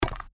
stepwood_2.wav